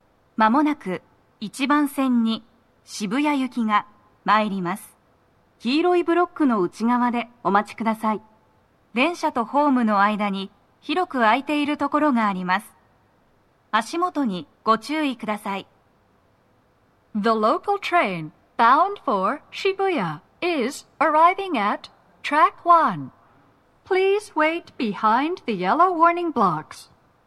鳴動は、やや遅めです。
1番線 渋谷方面 接近放送 【女声
接近放送1